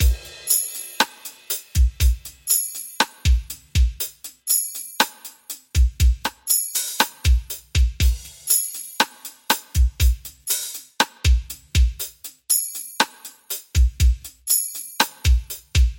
真实播放的慢速RnB
描述：用Roland TD25鼓组演奏和录音。添加了手鼓。
Tag: 120 bpm RnB Loops Drum Loops 2.69 MB wav Key : Unknown